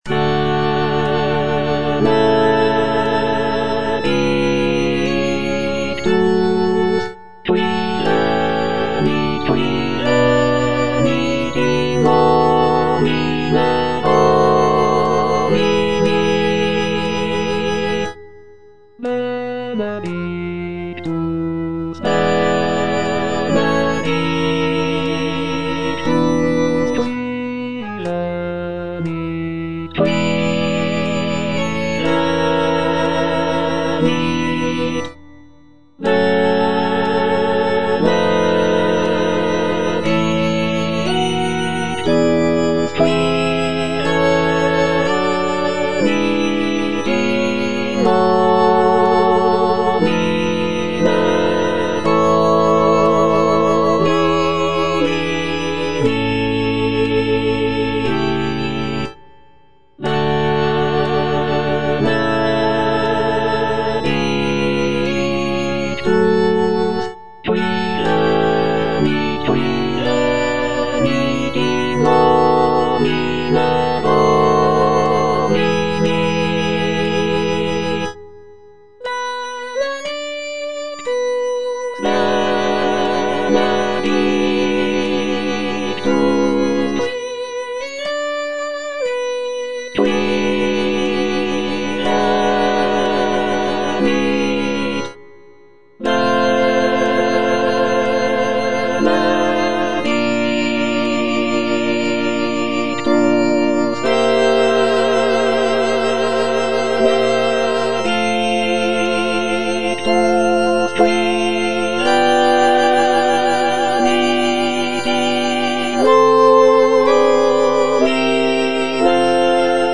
The piece features lush harmonies, soaring melodies, and powerful choral sections that evoke a sense of mourning and reverence.
F. VON SUPPÈ - MISSA PRO DEFUNCTIS/REQUIEM Benedictus (soprano II) (Voice with metronome) Ads stop: auto-stop